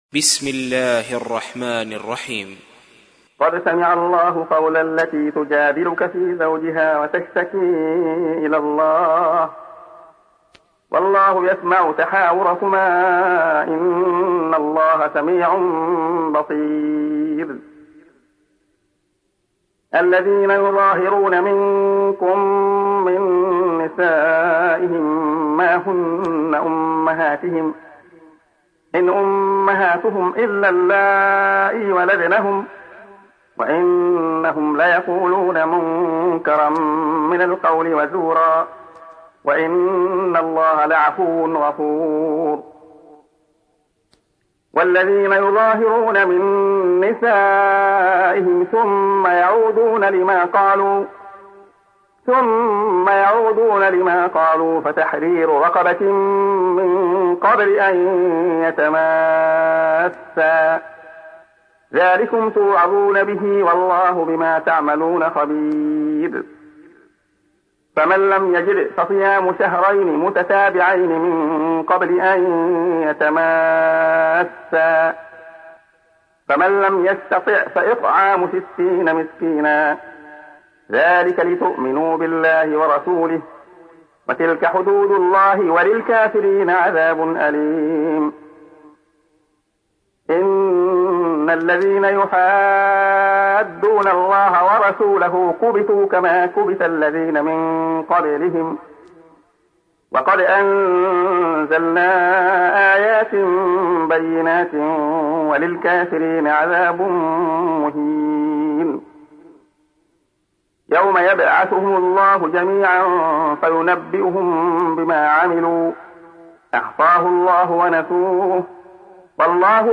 تحميل : 58. سورة المجادلة / القارئ عبد الله خياط / القرآن الكريم / موقع يا حسين